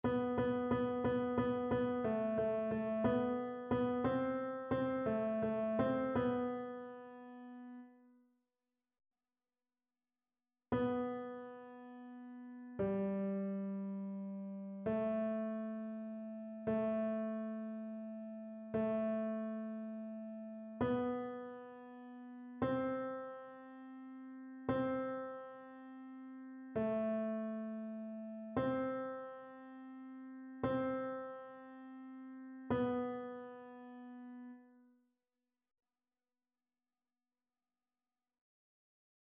Ténor
annee-abc-fetes-et-solennites-dedicace-des-eglises-psaume-83-tenor.mp3